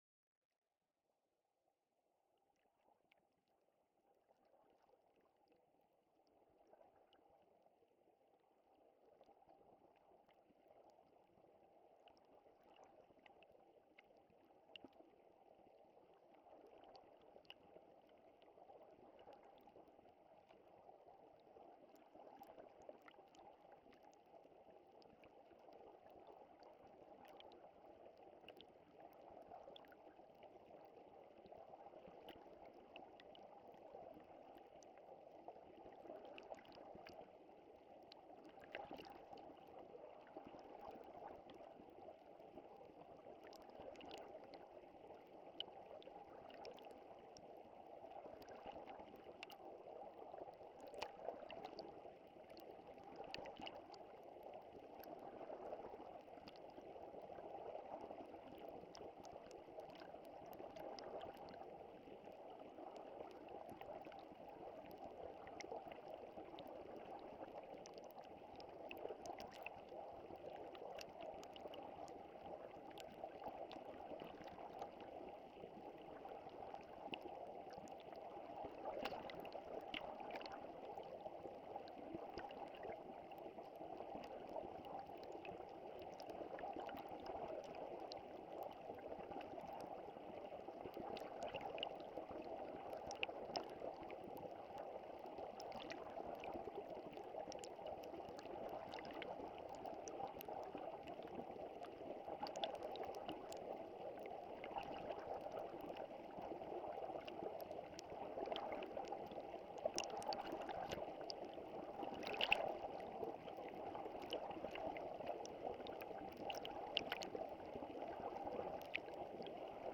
EL-ROMERAL.mp3